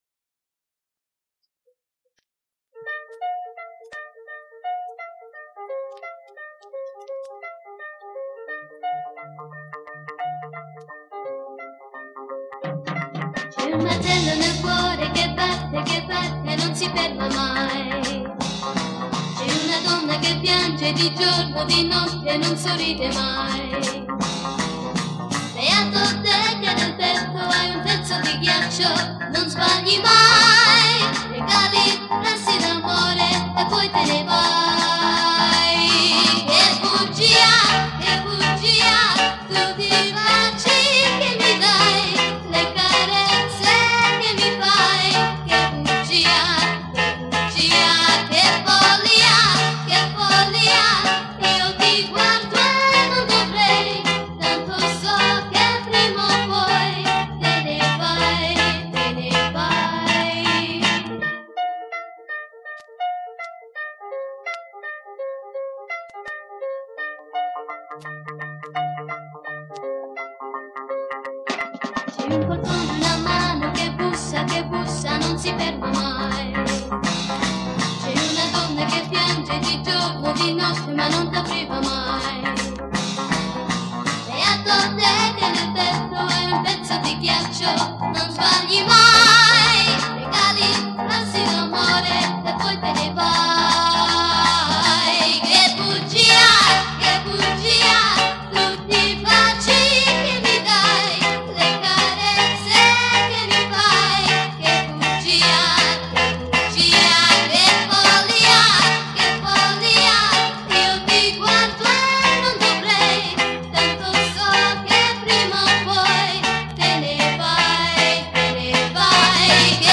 Piano Accordion, Organ, Strings & Electric Piano
Electric & Acoustic Guitars
Electric Bass
Drums
Brass Section
BACKGROUND VOCALS
Recorded at Riversound Recordings, Sydney